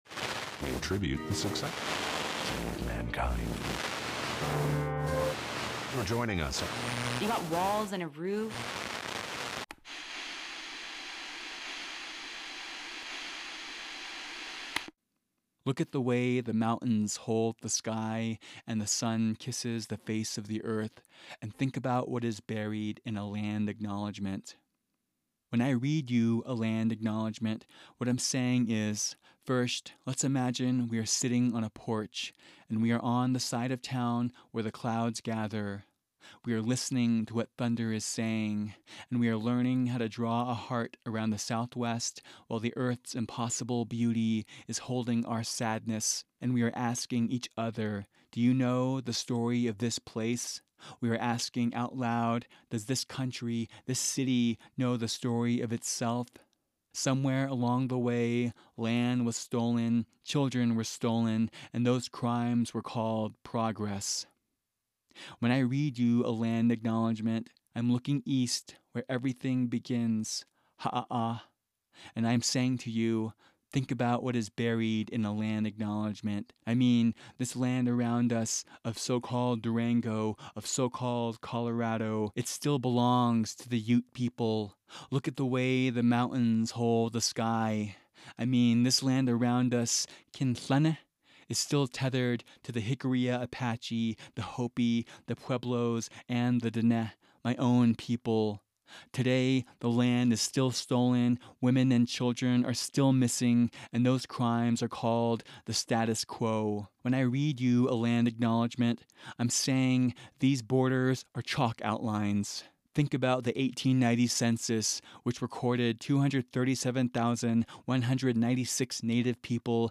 Poem: LOOK AT THE WAY THE MOUNTAINS HOLD THE SKY & THINK ABOUT WHAT IS BURIED IN A LAND ACKNOWLEDGEMENT
Sound design was provided by Epidemic Sound.